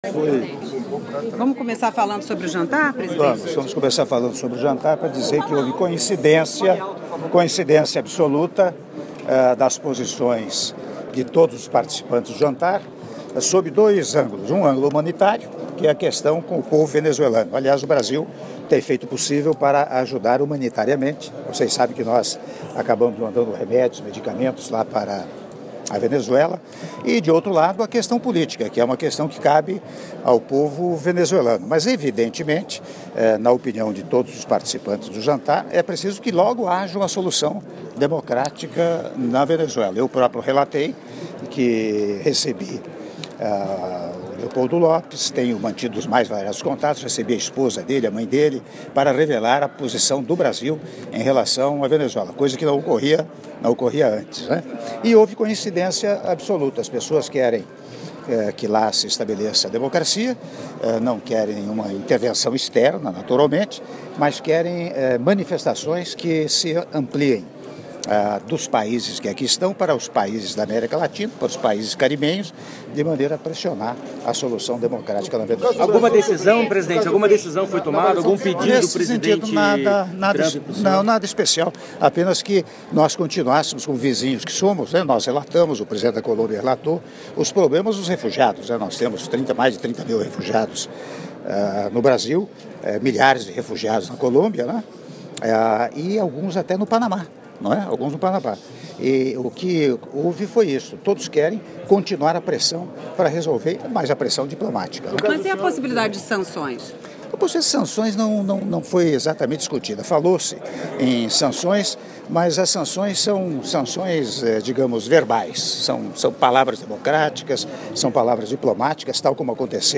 Áudio da entrevista coletiva concedida pelo Presidente da República, Michel Temer, após jantar oferecido pelo Presidente dos Estados Unidos da América, Donald Trump - Nova York/EUA (02min18s)